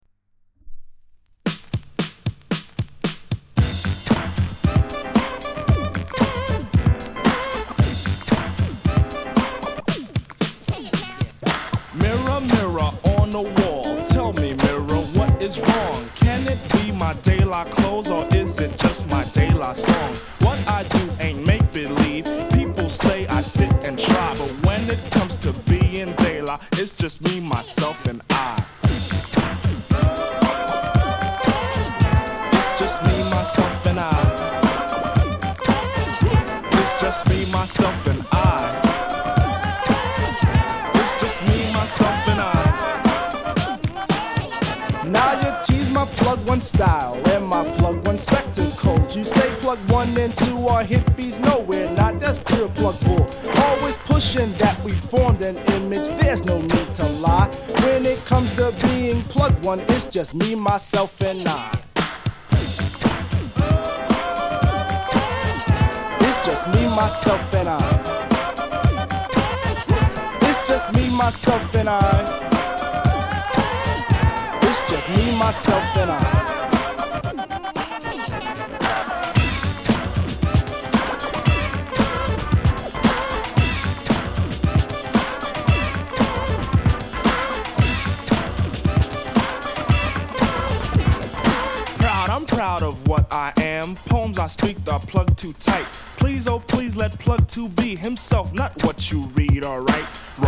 類別 饒舌、嘻哈